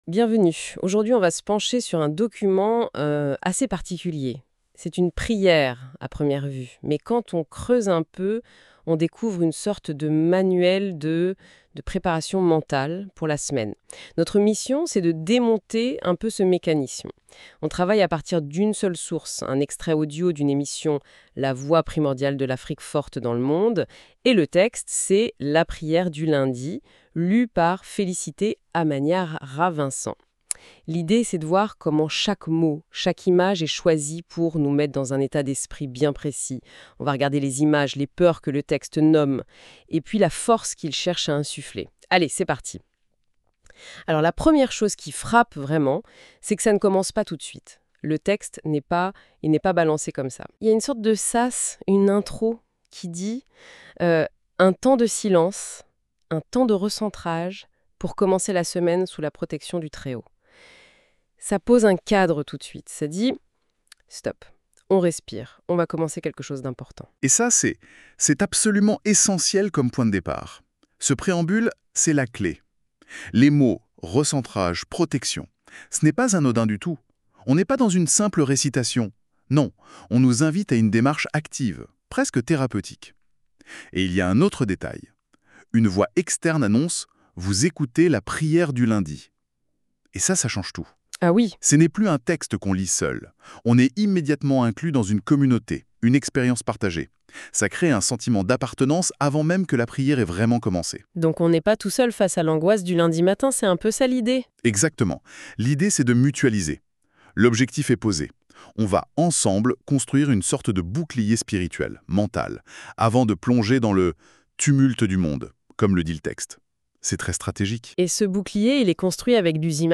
PRIÈRE DU LUNDI